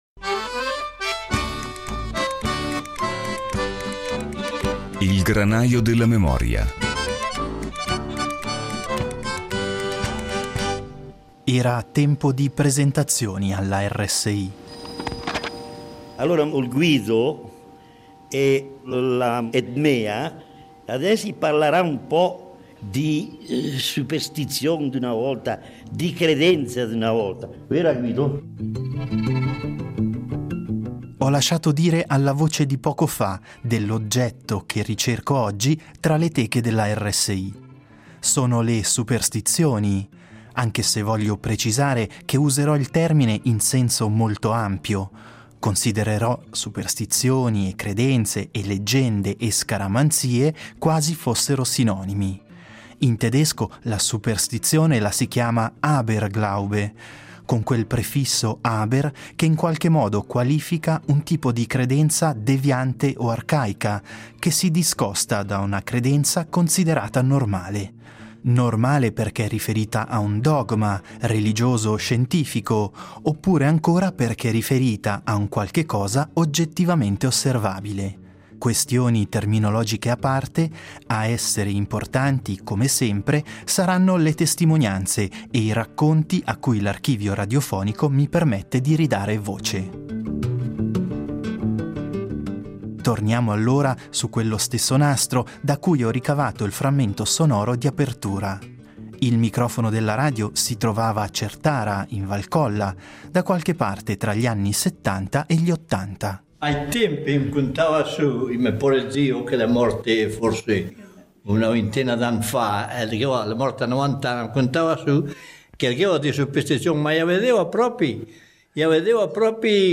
I documenti sonori recuperati dall’archivio radiofonico racconteranno di credenze che sarebbe tuttavia troppo facile etichettare come pratiche irrazionali. Resistenti e persistenti, le superstizioni possono infatti essere viste come uno dei tanti modi per interpretare la complessa e imprevedibile realtà nella quale siamo quotidianamente immersi.